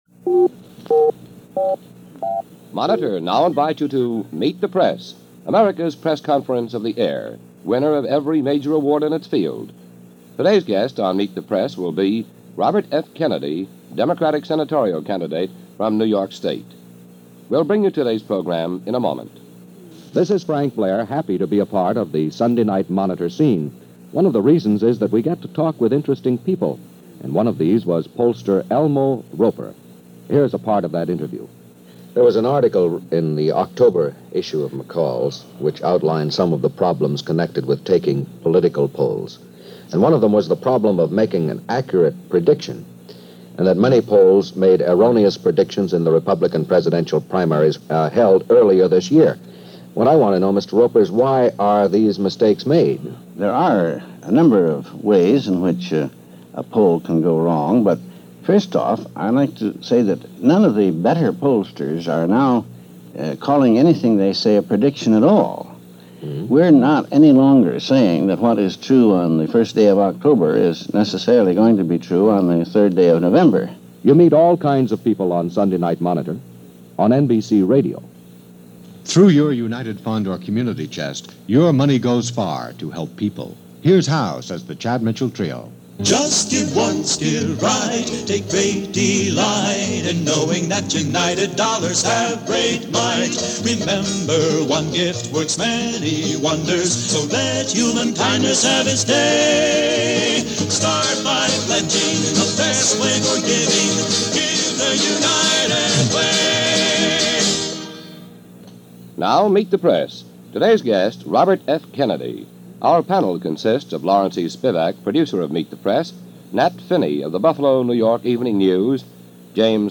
To get an idea of just how much the climate of politics has changed in this country, you only have to listen to the first 5 minutes of this Interview via NBC’s Meet The Press from October 18, 1964 to get an idea of just how far we’ve fallen down on the job of Professional Journalism.
Rather than the acknowledged format today of one interviewer and one interviewee, the format during this 1964 exchange featured Senatorial Candidate from New York Robert F. Kennedy facing a panel of some three journalists; all of whom asked some pretty hard-ball questions.